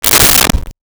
Door Close 01
Door Close 01.wav